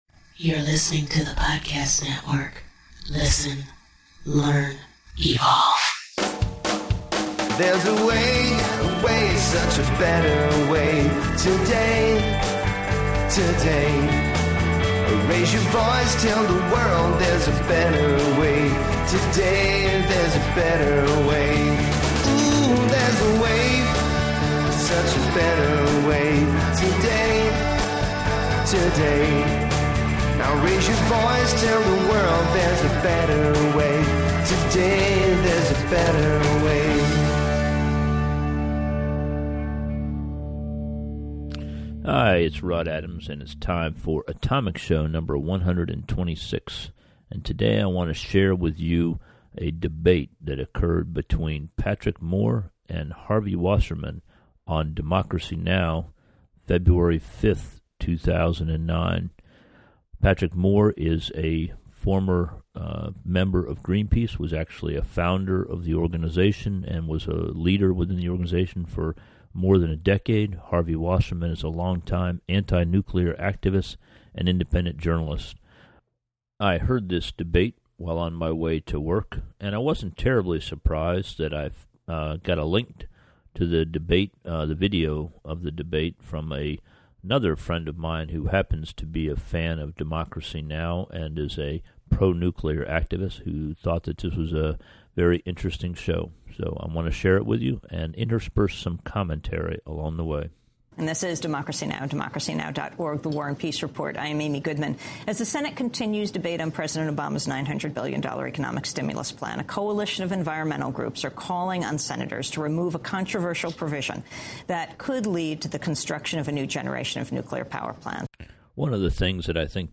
On Thursday February 5, 2009, Amy Goodman of Democracy Now! moderated a brief debate about nuclear power between Harvey Wasserman and Patrick Moore.